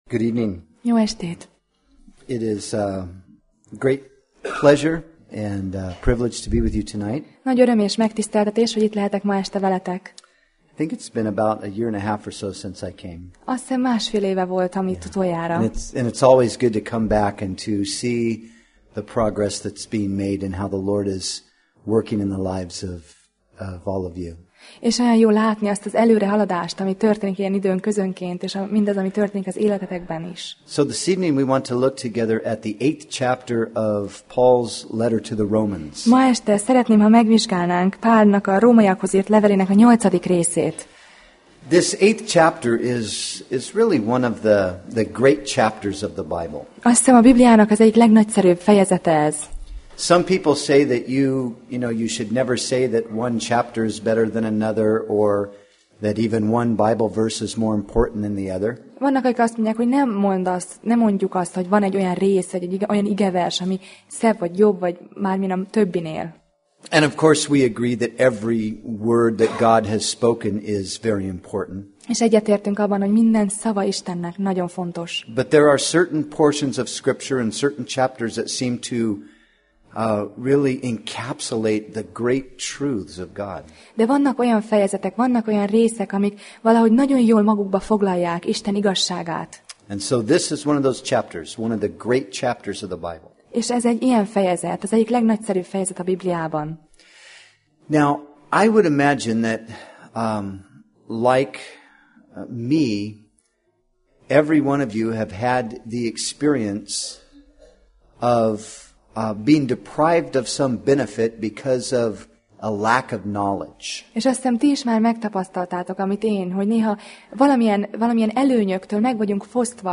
Sorozat: Tematikus tanítás Passage: Róma (Romans) 8:1-39 Alkalom: Szerda Este